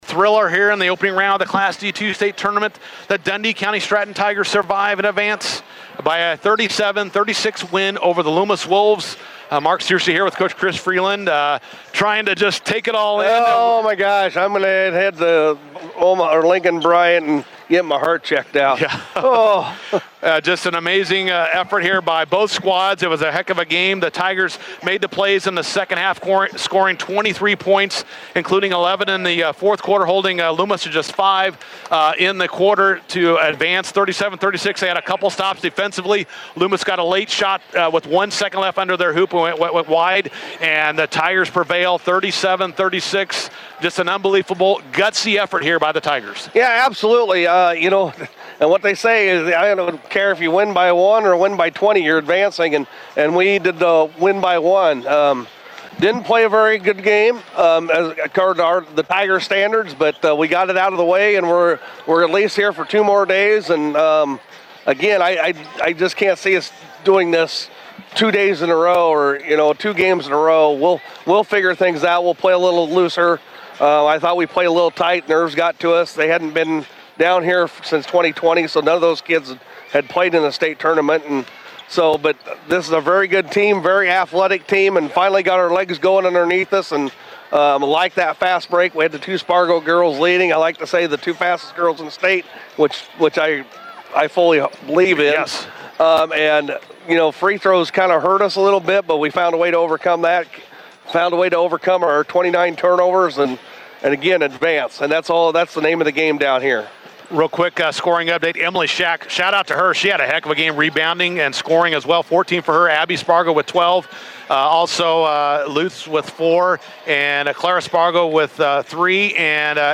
INTERVIEW: Dundy County-Stratton girls edge Loomis in Class D2 quarterfinals.